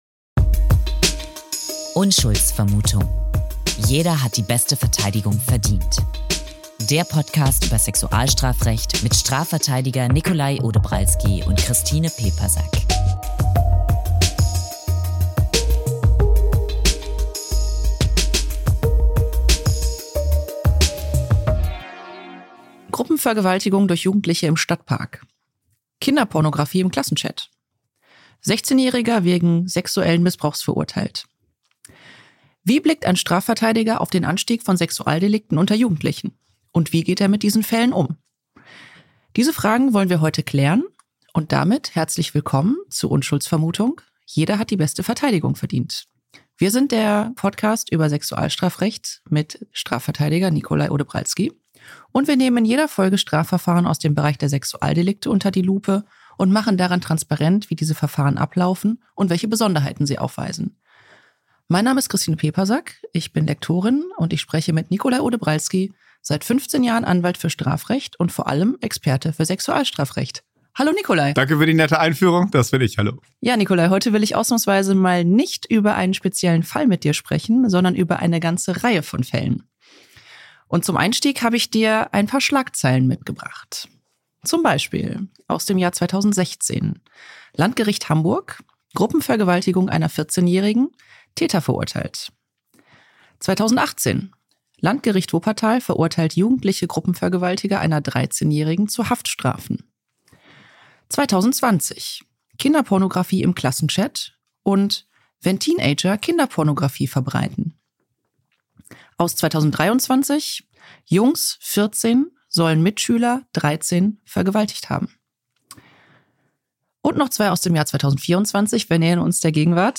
Wie erlebt ein Strafverteidiger für Sexualstraftaten jugendliche Beschuldigte? In dieser Folge sprechen Strafverteidiger